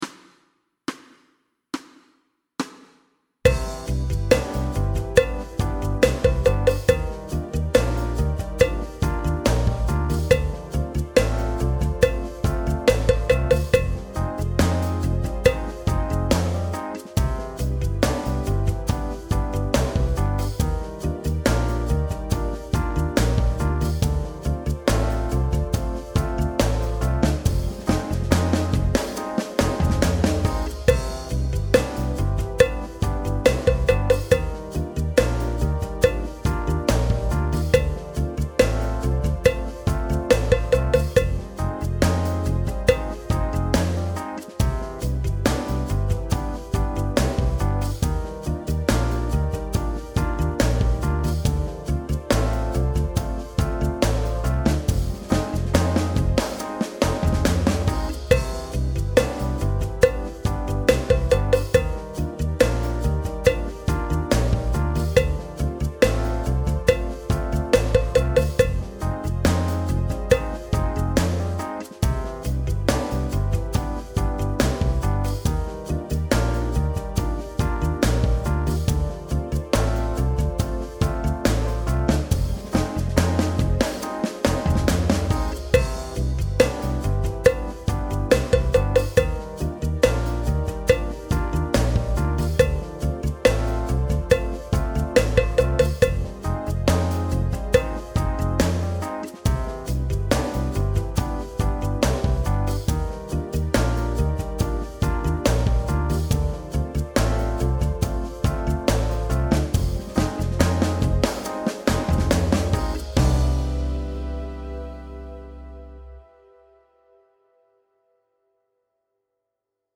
Lyt til rytmen i takt 1-4. Gentag/imitér rytmen i takt 5-8.
Elementer i dette kapitel er: Trioler, 1/4´, 1/8´ og 16`dels noder og pauser.